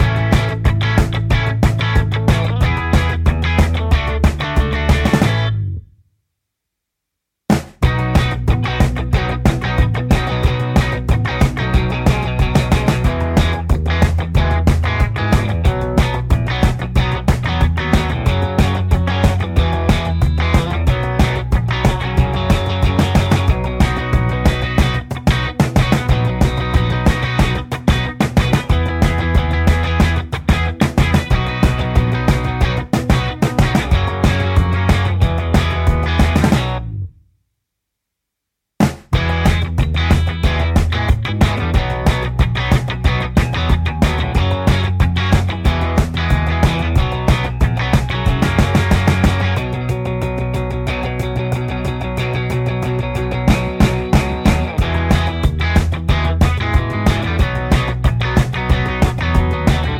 No Backing Vocals Punk 2:00 Buy £1.50